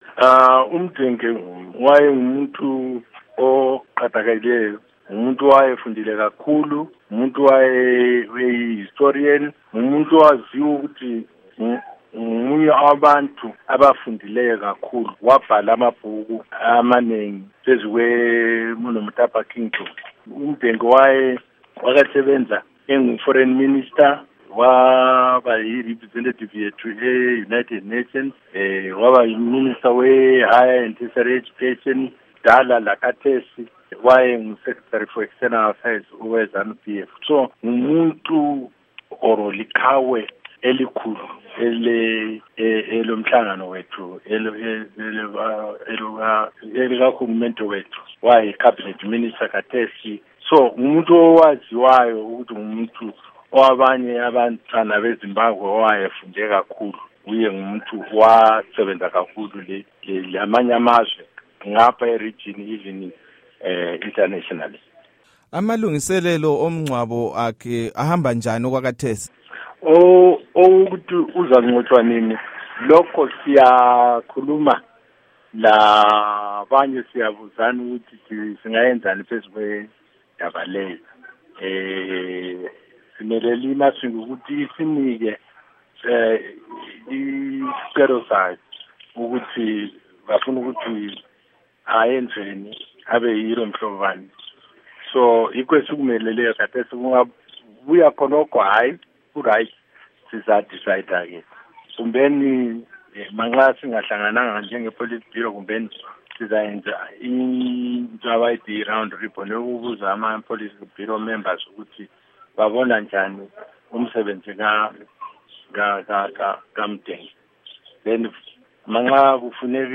Embed share Ingxoxo loMnu. Rugare Gumbo by VOA Embed share The code has been copied to your clipboard.